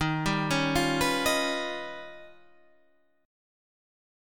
D# Augmented 9th